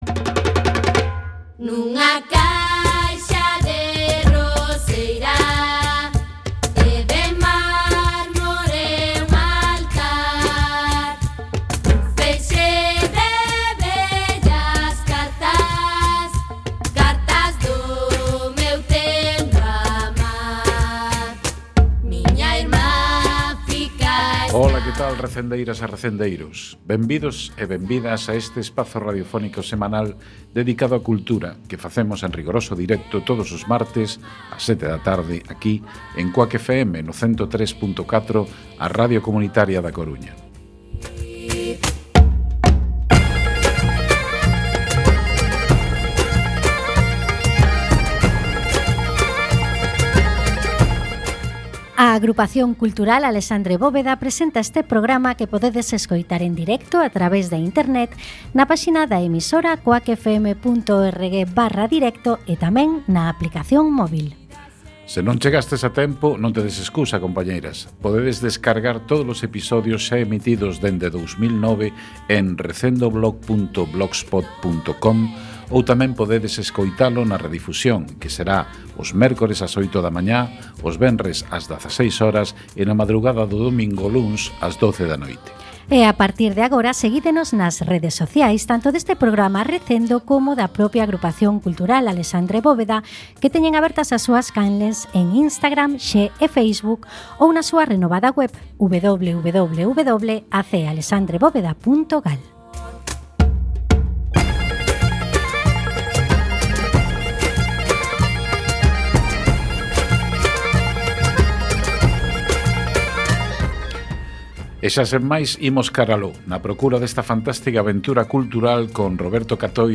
17x3 Entrevista